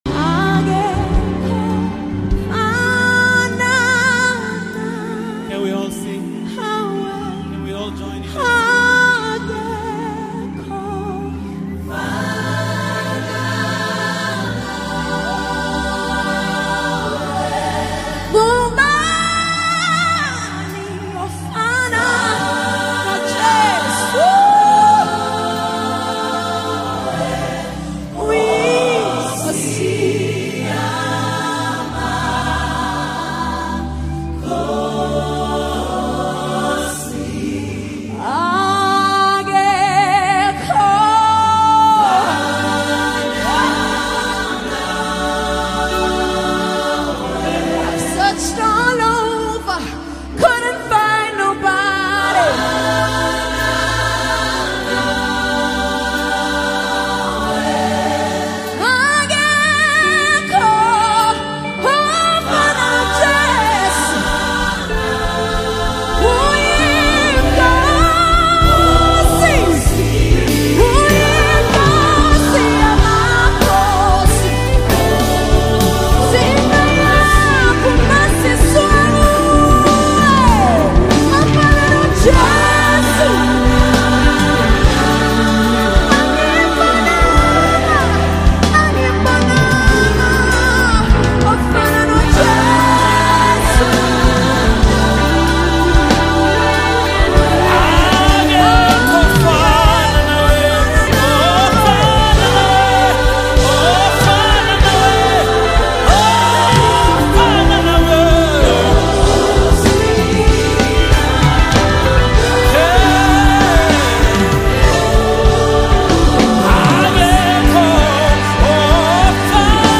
A powerful song declaring God’s uniqueness and greatness
Heartfelt worship with anointed vocals
📅 Category: South African Deep Worship Song 2025